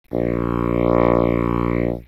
Below is the sound of a bassoon, altered to give the impression that it is traveling around the listener. No reverberation is added to the first sample, yet an informal poll finds that most hear the bassoon begin in front of the listener (slightly to the right of front due to silence at the beginning of the sound file) and then travel behind the listener and around the left to front again.
Bassoon traveling circular path
bassoon.round2.wav